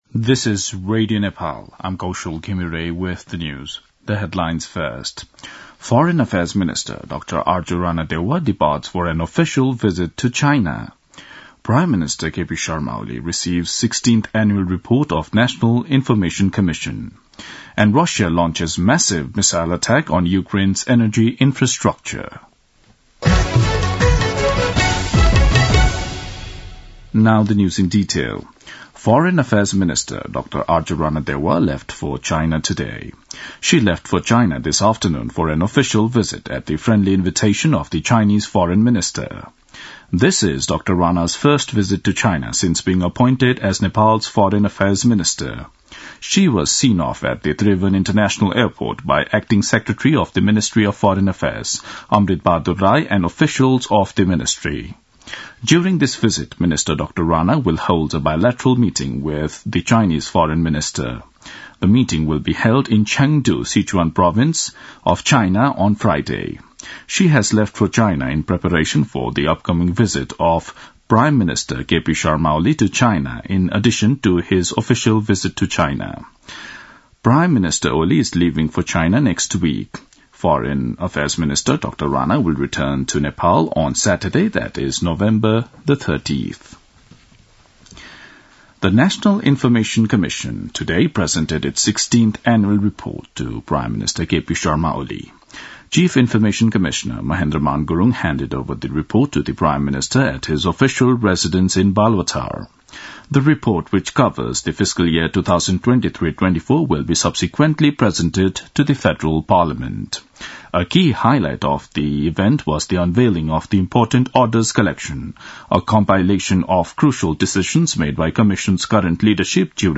दिउँसो २ बजेको अङ्ग्रेजी समाचार : १४ मंसिर , २०८१
2-pm-english-news-1-11.mp3